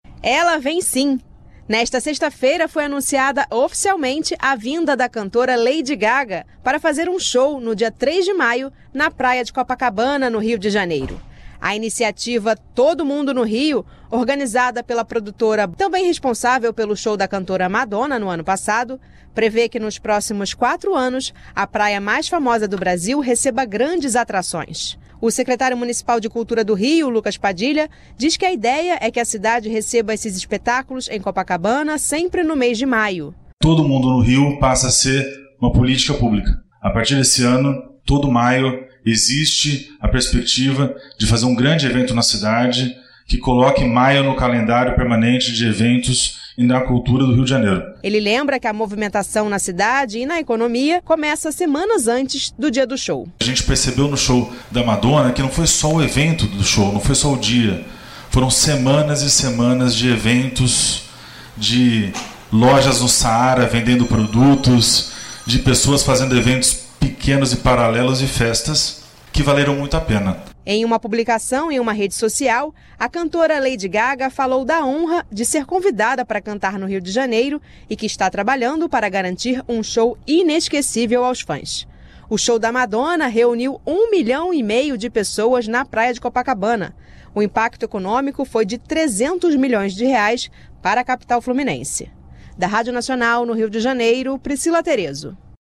O Secretário Municipal de Cultura do Rio, Lucas Padilha, diz que a ideia é que a cidade receba esses espetáculos em Copacabana, sempre no mês de maio.